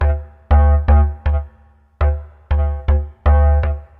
loops basses dance 120 - 2
Basse dance 6 C